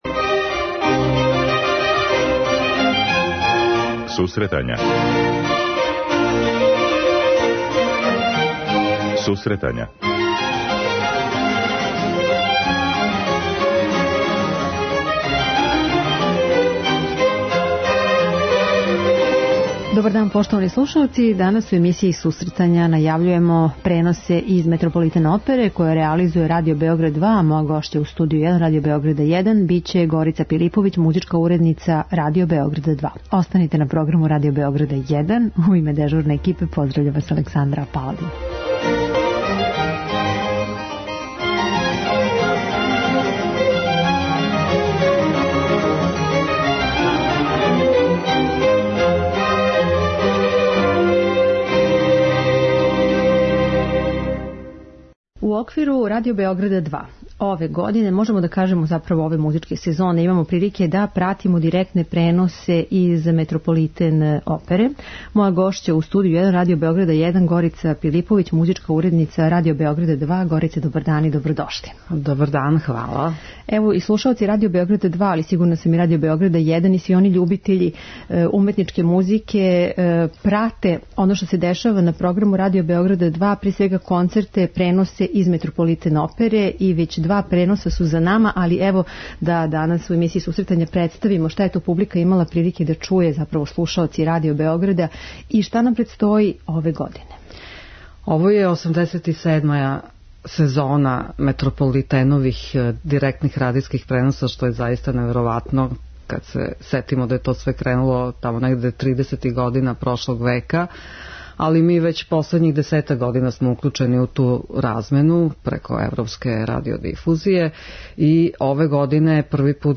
преузми : 10.78 MB Сусретања Autor: Музичка редакција Емисија за оне који воле уметничку музику.